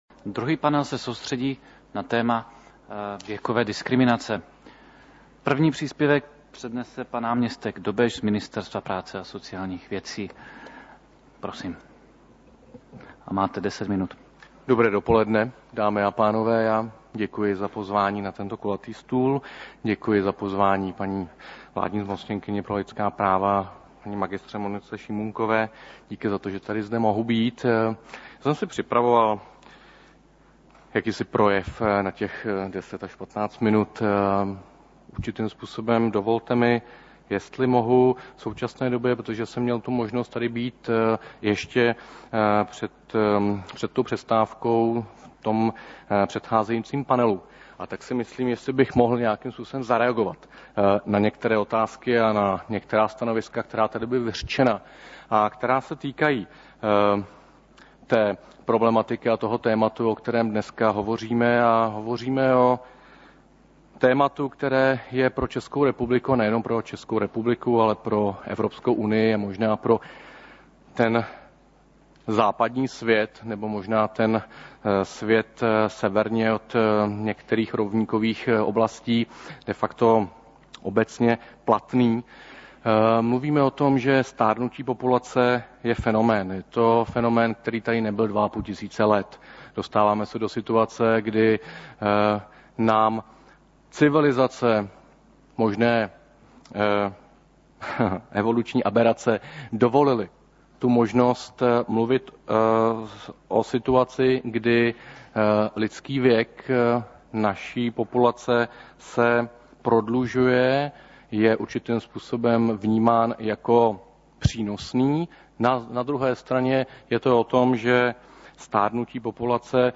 Dne 20. listopadu 2012 se ve Strakově akademii konala diskuze o právech starších osob, kterou pořádala zmocněnkyně vlády pro lidská práva při příležitosti Evropského roku aktivního stárnutí a mezigenerační solidarity.
Audio záznam druhého panelu